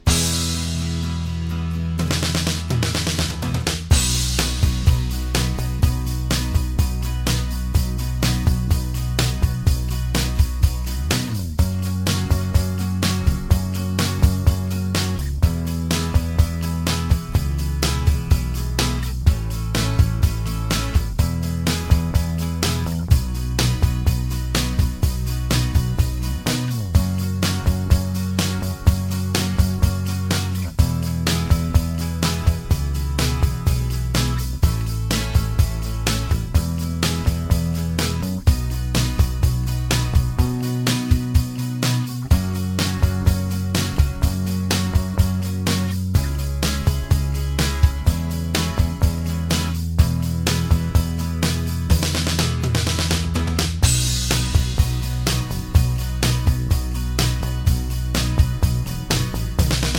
Minus Main Guitar For Guitarists 3:38 Buy £1.50